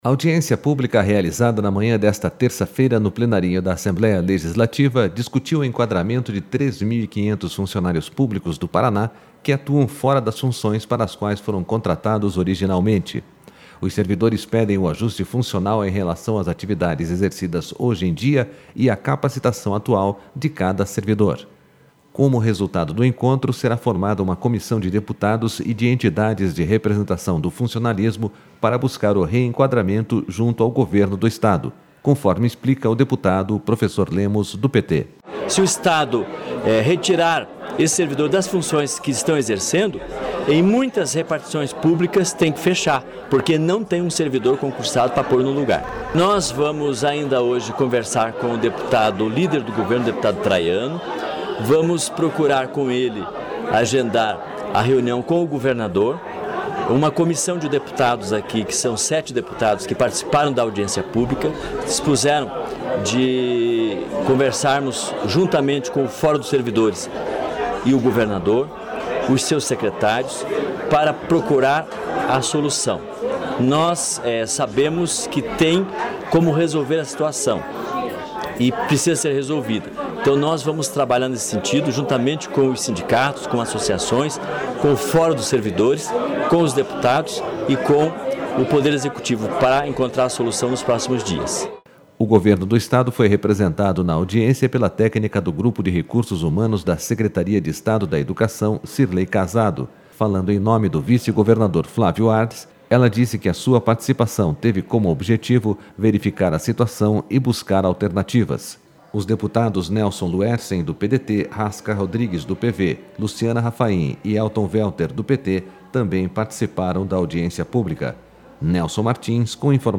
Audiência pública debate enquadramento dos servidores públicos